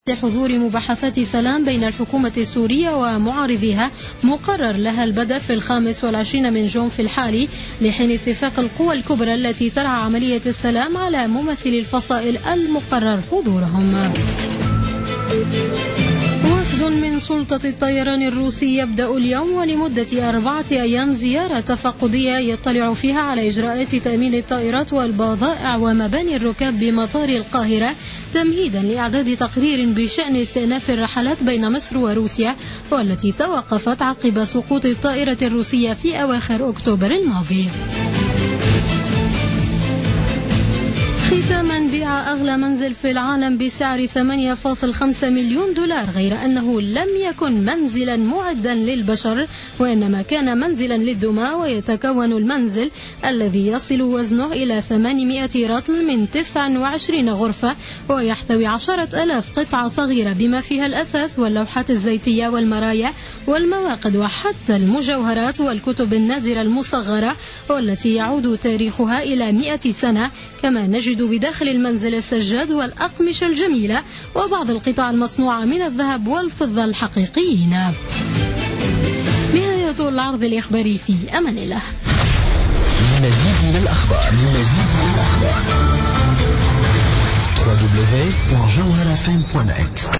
نشرة أخبار منتصف الليل ليوم الثلاثاء 19 جانفي 2016